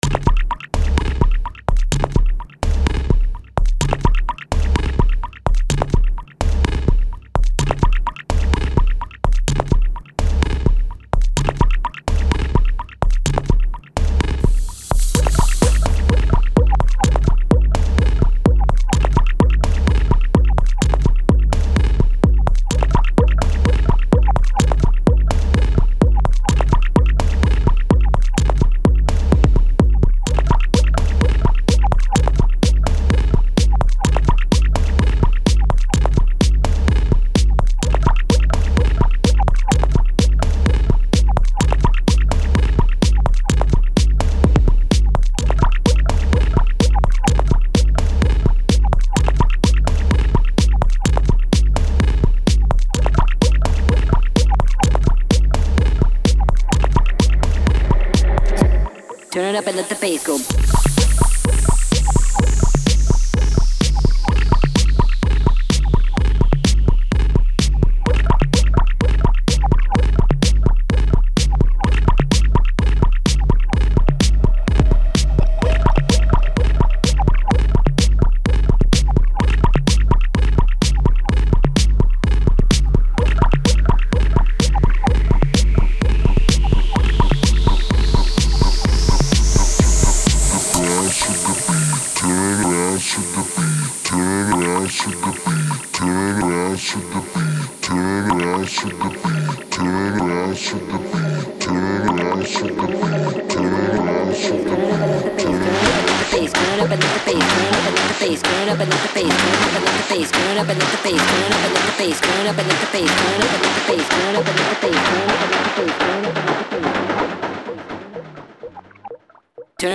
Quite energetic electro with strong techno influences.